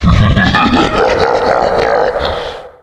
Смех какого-то злодея